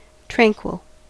Ääntäminen
IPA : /ˈtɹæŋ.kwɪl/